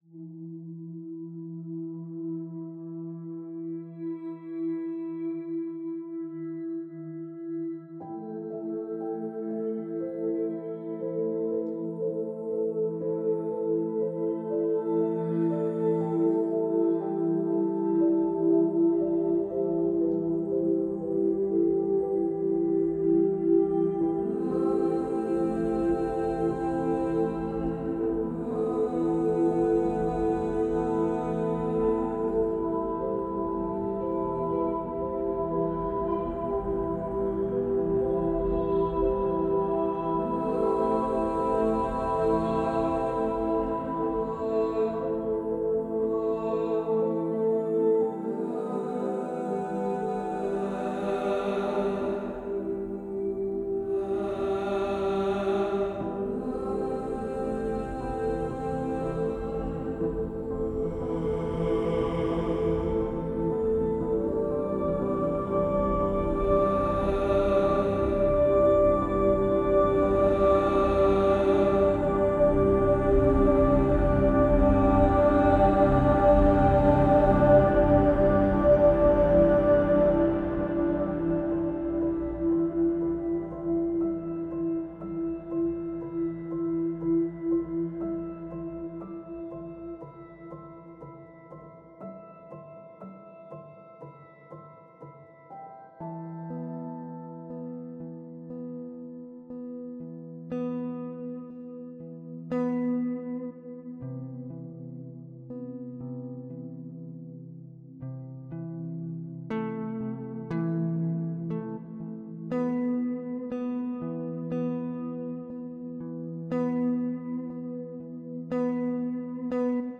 Some experimental choir-stuff for your listening pleasure.